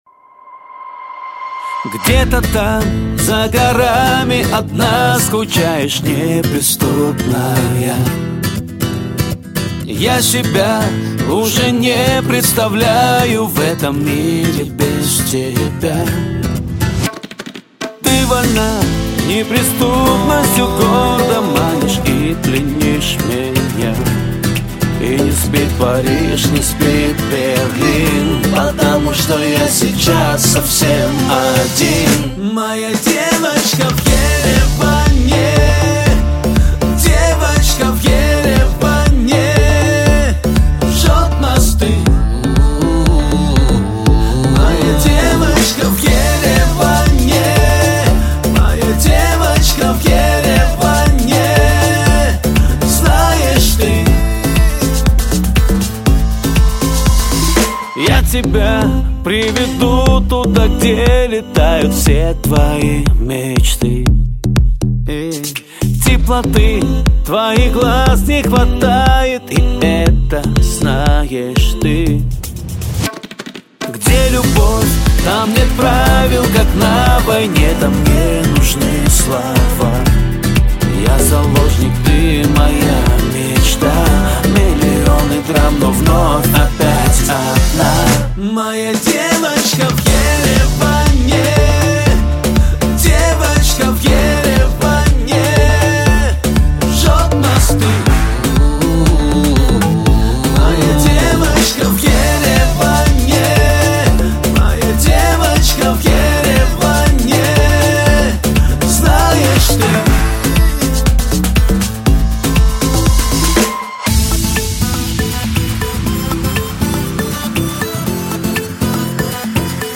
Армянская музыка, Erger 2018